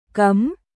CấmProhibited禁止されているカム